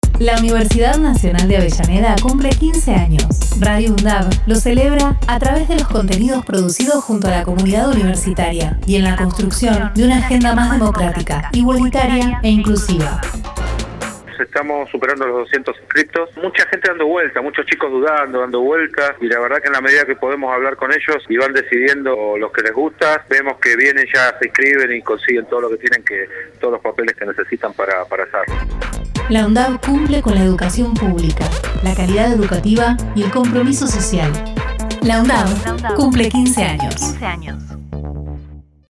Jorge Calzoni (Archivo Radio UNDAV - 2011)
Spot LaUndavCumple_Calzoni_0.mp3